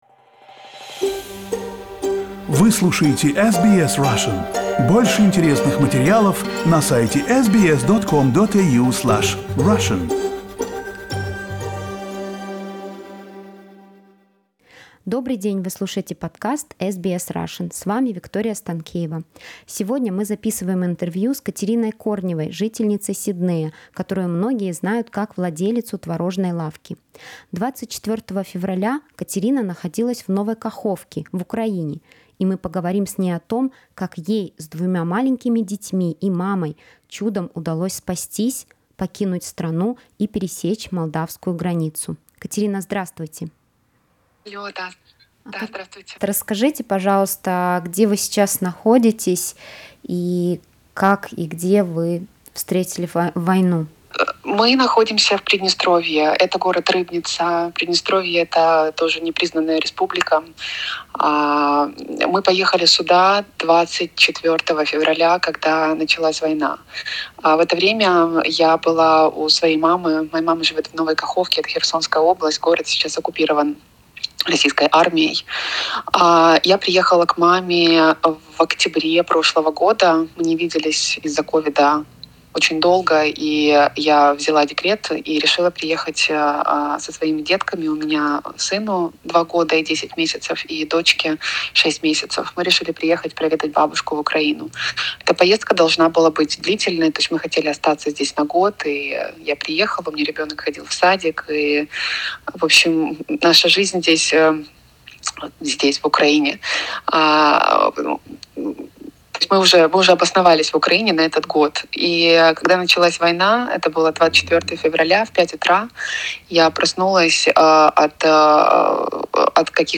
В интервью она рассказала, как это было и что происходит сейчас.